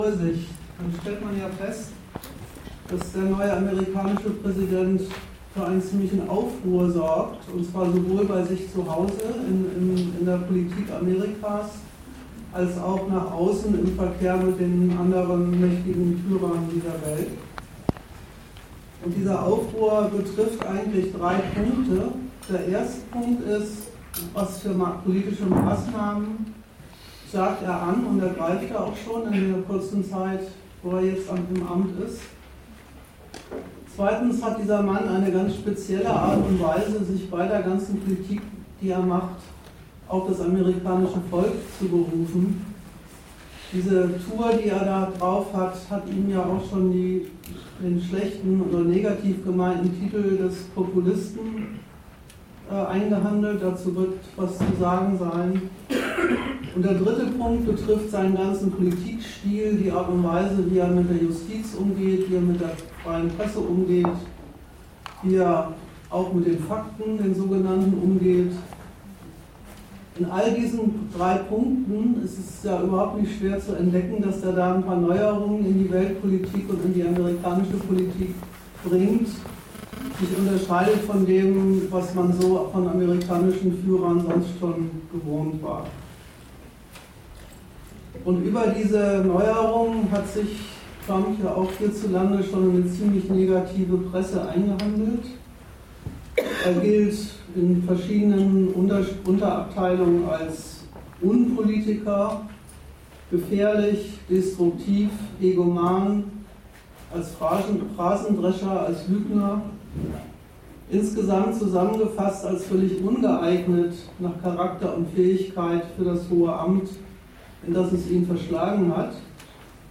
Datum 27.04.2017 Ort Bremen Themenbereich Rechtsstaat und Demokratie Veranstalter Argudiss Dozent Gastreferenten der Zeitschrift GegenStandpunkt Dass Trump in Deutschland keine gute Presse hat, verwundert uns nicht.